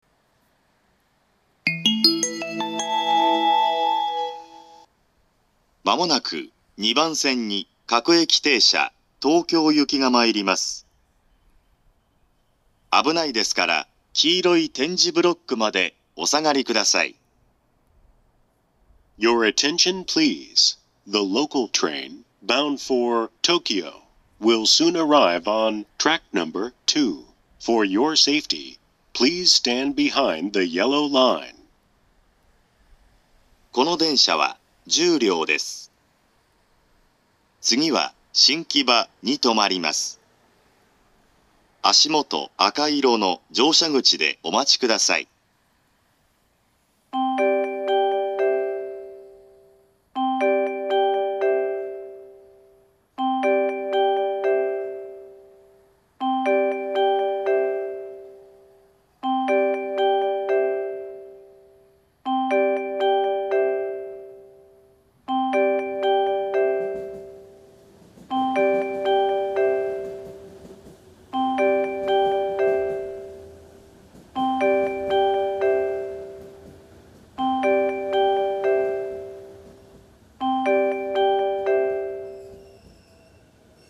２０１６年９月２５日には、自動放送がＡＴＯＳ型放送に更新されています。
２番線接近放送
２番線到着放送